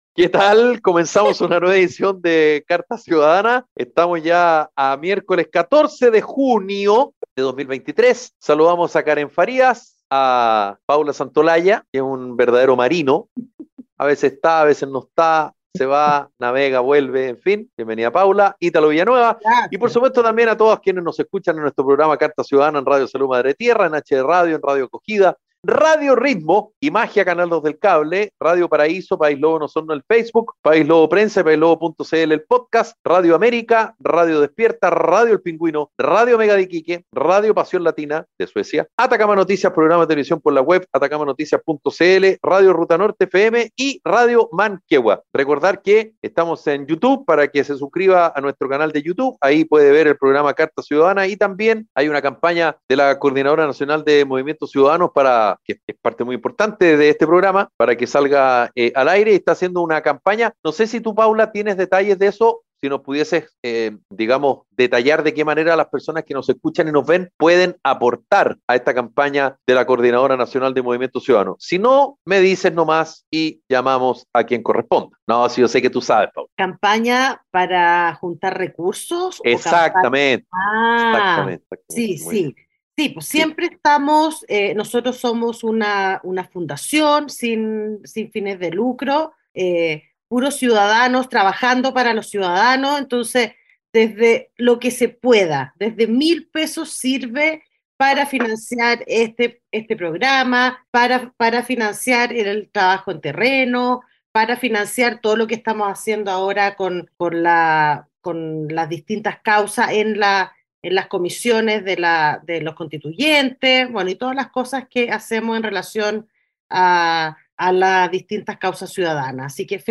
Un programa radial de conversación y análisis sobre la actualidad nacional e internacional.